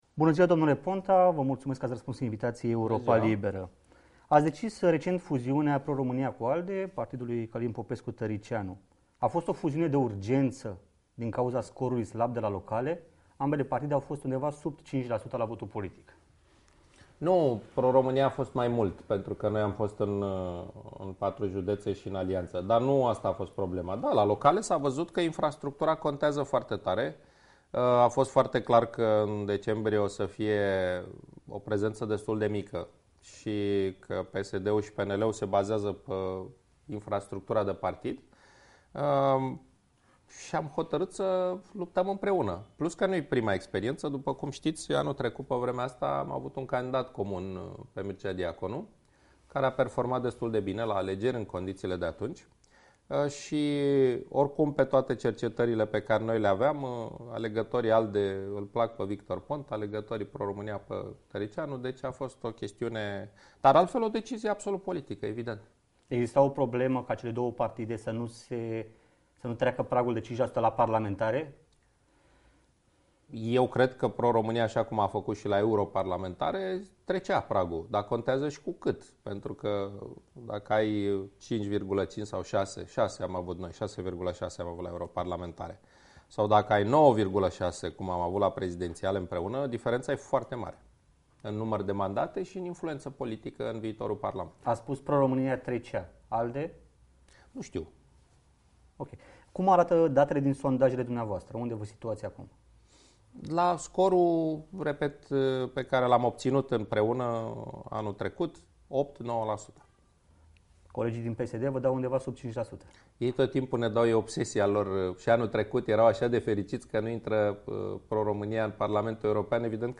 AUDIO Interviu integral cu Victor Ponta